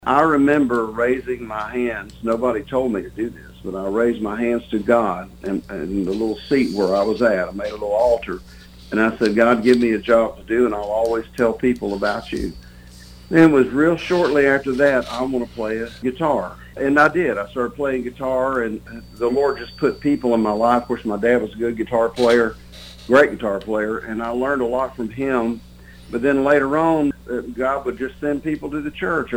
In a recent interview on KTTN, Dykes said he has loved guitar since he was a child.